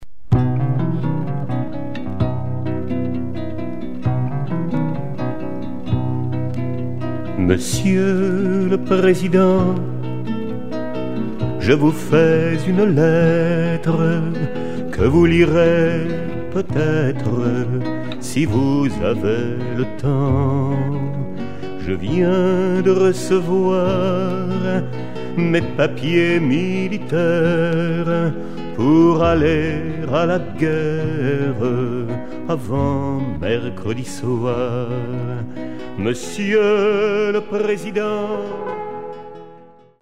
Arrangements & direction